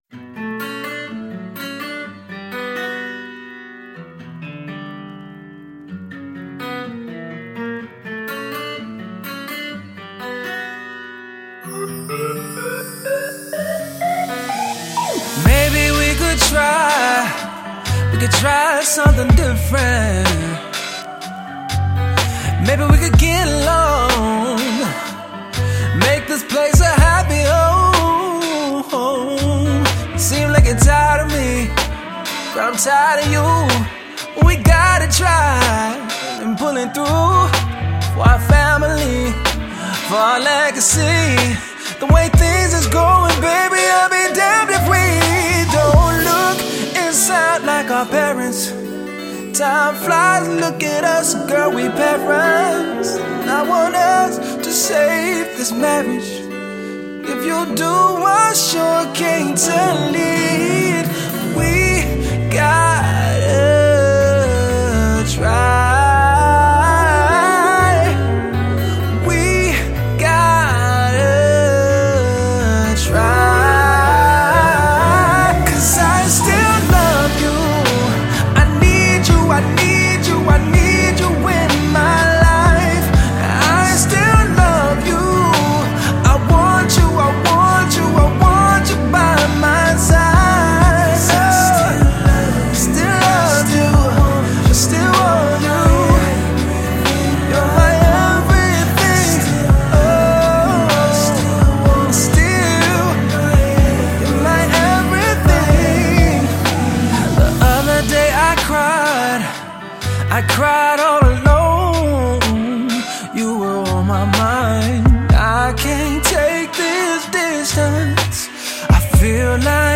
Pop/R&B